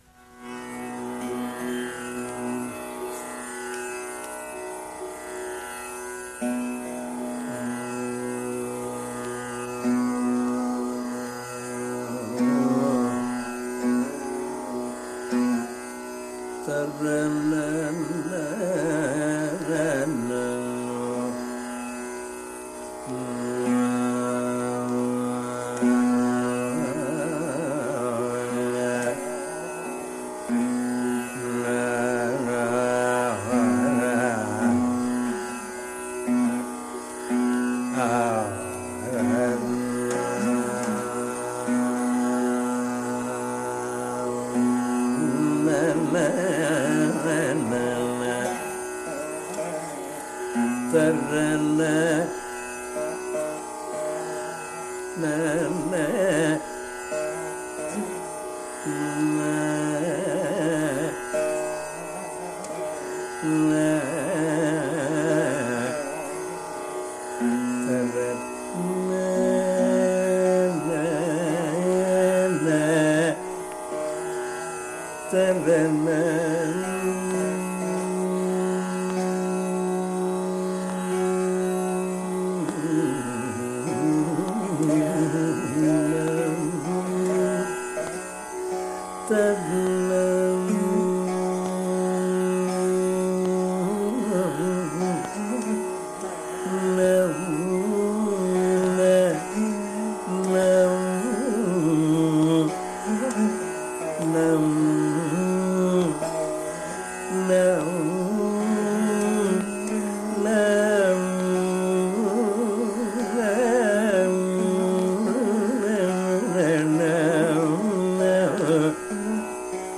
Ālāpana begins and gradually extends upwards in pitch
vina player imitates/repeats each sung line
Rāga Śankarabharana parallels the western major scale and the śuddha that (Bilāval thāt) of Hindustani sangit.